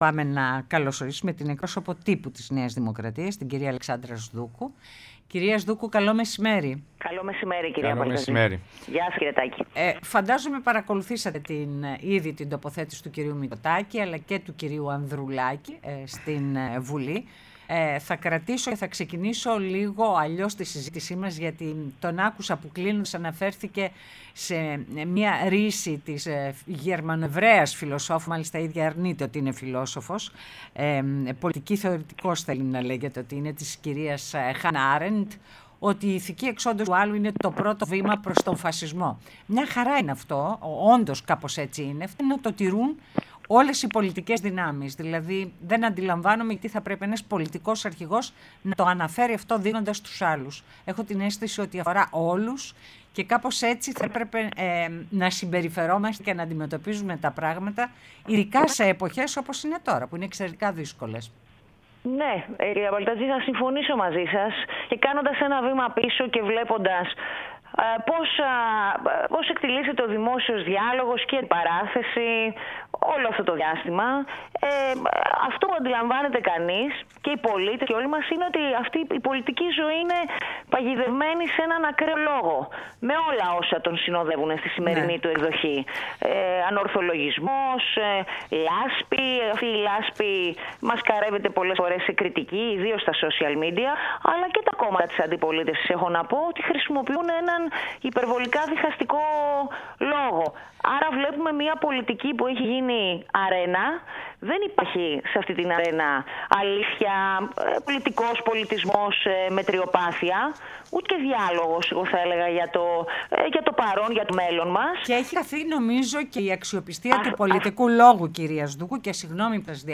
Η Αλεξάνδρα Σδούκου, εκπρόσωπος Τύπου ΝΔ, μίλησε στην εκπομπή «Ναι, μεν Αλλά»